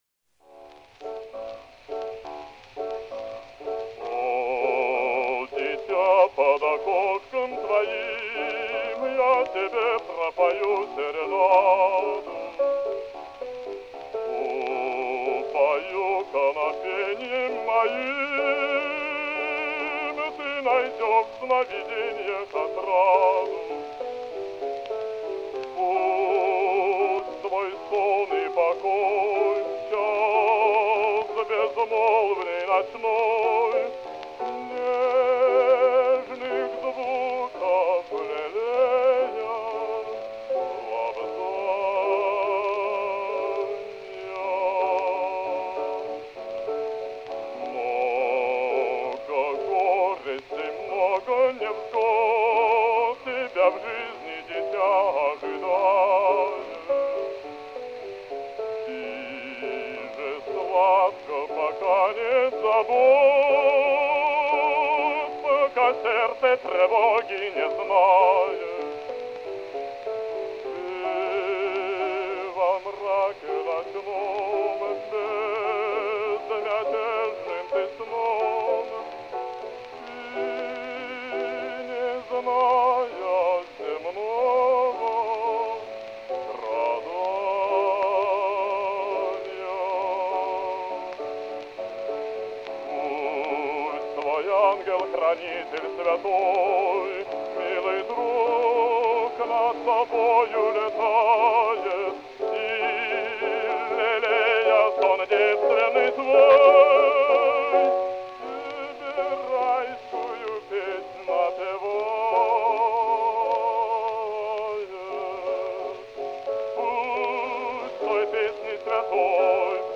Слова К. Р. Серенада «О, дитя». Исполняет В. И. Касторский.